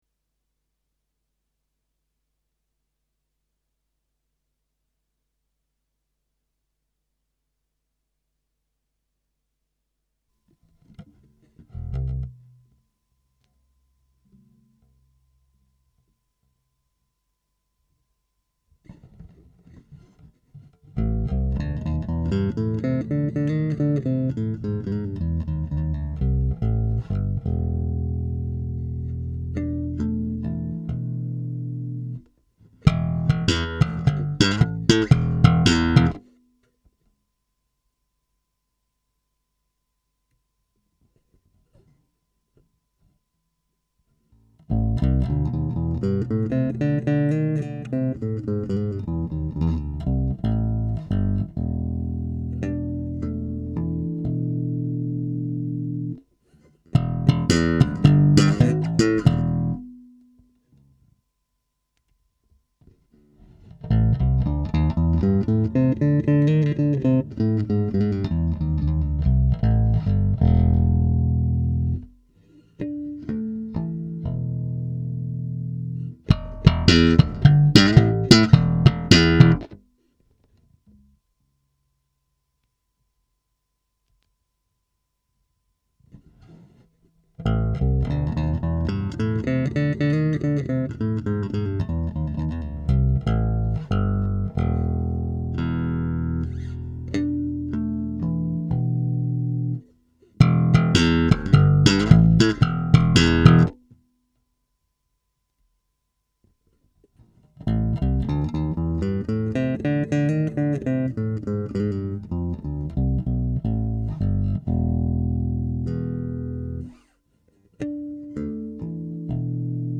Kommt direkt aus dem DI vom MarkBass Randy Jackson. EQ-Flat. Im Mischpult Soundcraft UI24 auch Flat. Am Bass auch kein Ton-Korrektur. Das erste Paar ist MM als SingleCoil, zweite Paar ist MM-Parallel, Dritte Paar ist MM-Seriell, vierte Paar: MM-SingleCoil + P-Bass, 5. ist: MM-Parallel + P-Bass, 6. ist: MM-Serie + P-Bass, 7. ist: Nur P-Bass und zuletzt der Killer: P-Bass und MM-Serie in Reihe (...zum slappen einfach zu heftig) Ich benutzte zum Slappen MM-Serie + P-Bass, und zum Fingerstyle: P-Bass und MM-Serie in Reihe geschaltet...